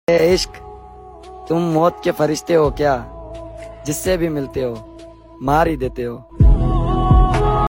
Urdu Poetry